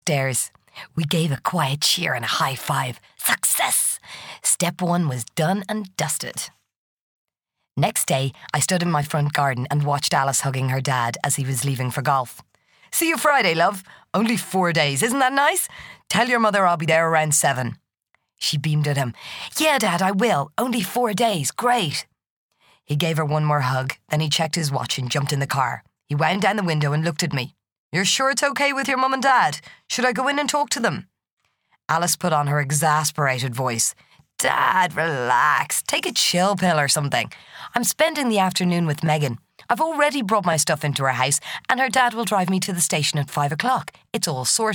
Audio Book Voice Over Narrators
Adult (30-50)